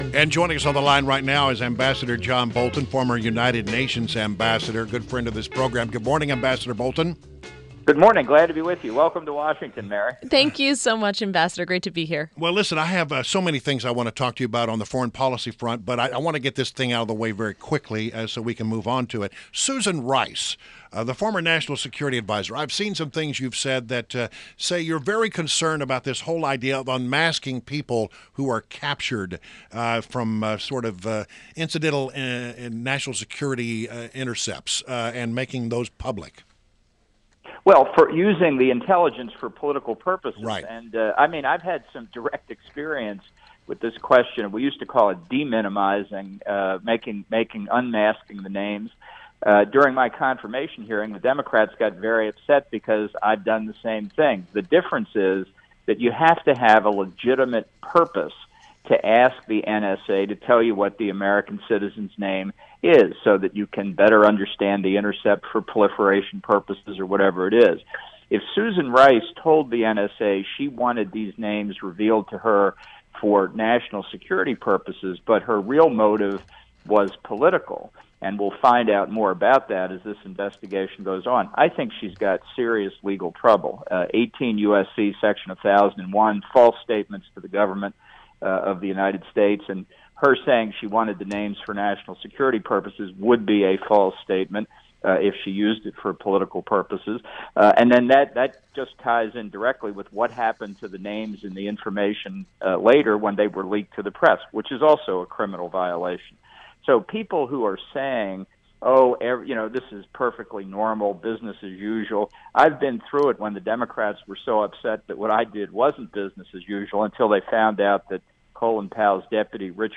INTERVIEW -- AMB. JOHN BOLTON - former United Nations Ambassador